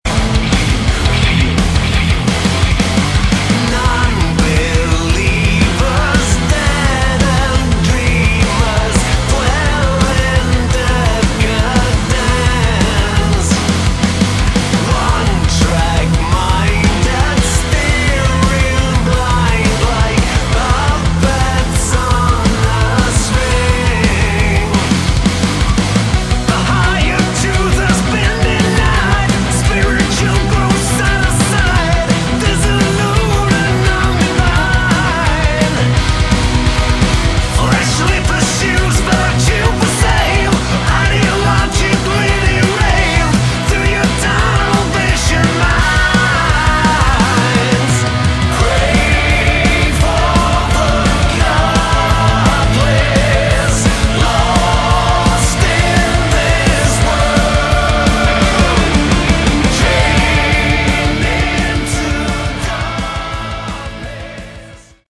Category: Hard Rock / Melodic Metal
guitars, keyboards
bass
drums